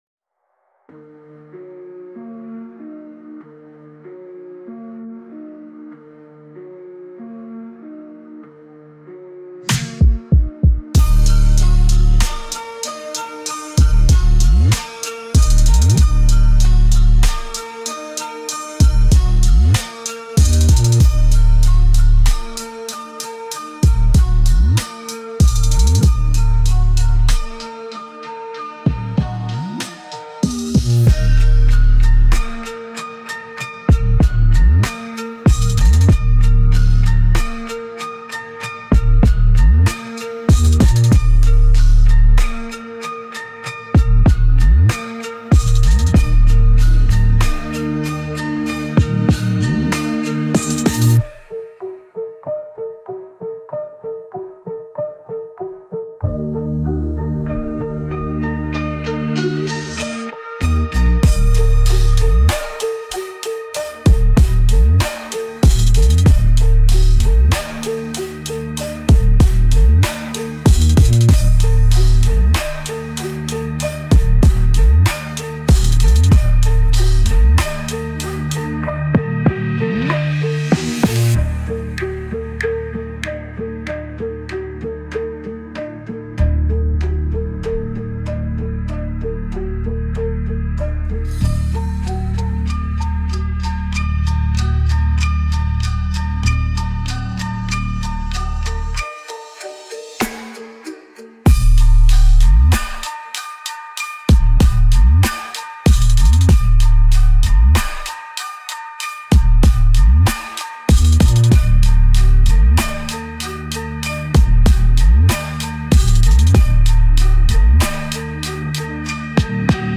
Instrumental - RLMradio Dot XYZ - 4 mins.mp3